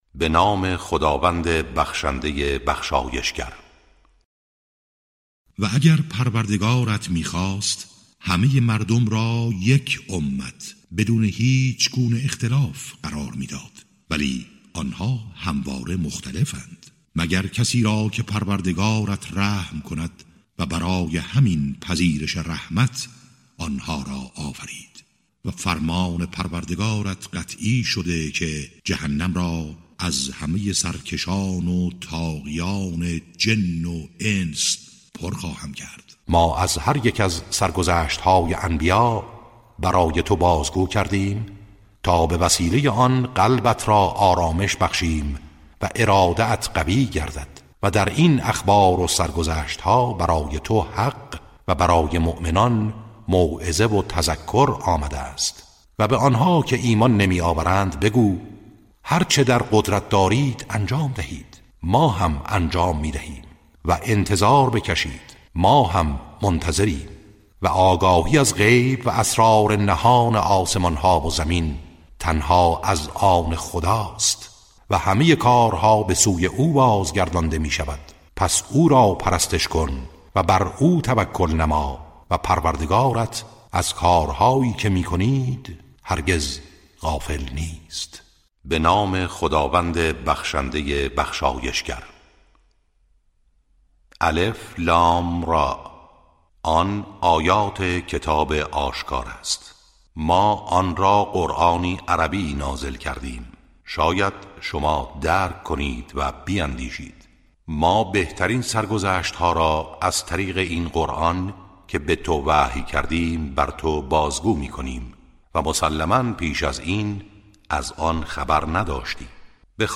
ترتیل صفحه ۲۳۵ سوره مبارکه هود و یوسف(جزء دوازدهم)
ترتیل سوره(هود، یوسف)